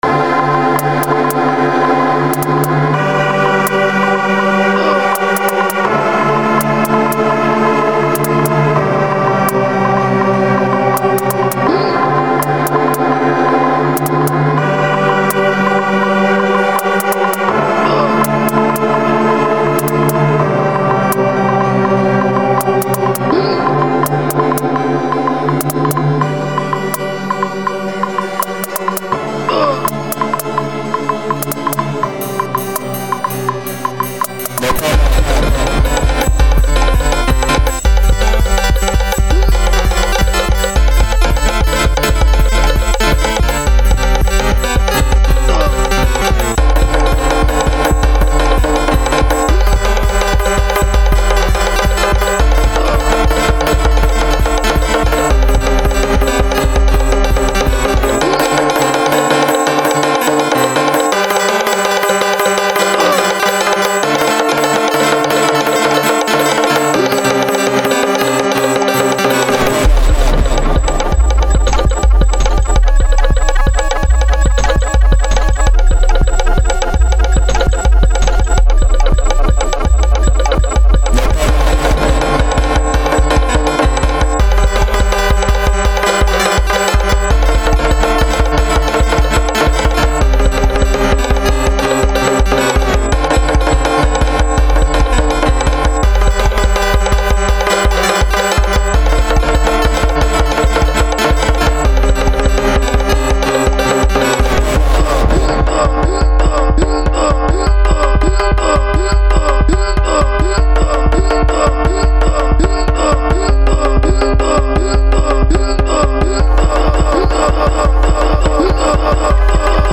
Sounds like a Casio SA-1 with too much voltage ran through it
even if it's DADAist it's still juke bro